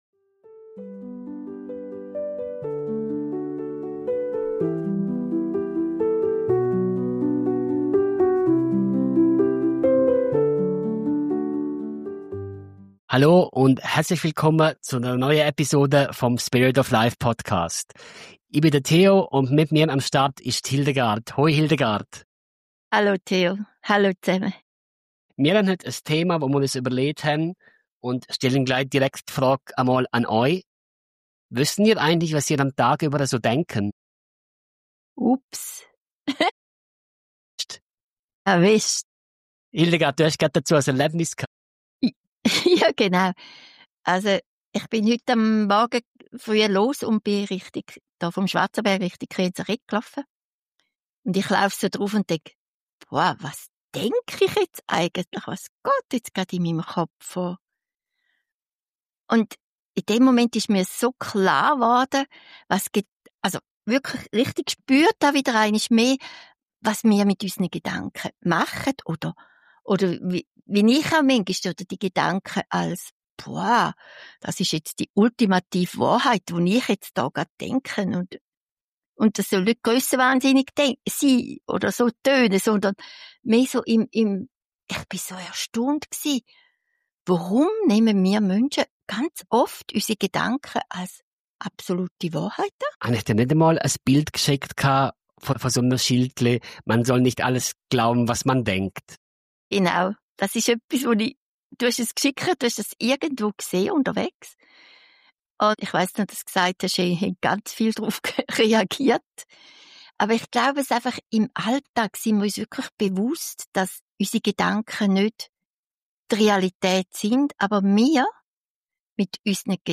Ein inspirierendes Gespräch darüber, wie du mehr Leichtigkeit in dein Denken bringst – und dadurch in dein Leben.